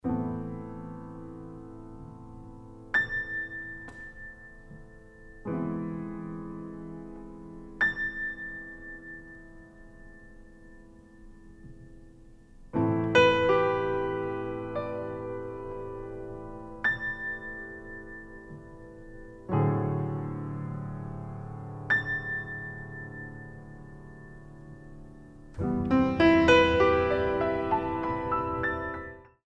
piano and percussion music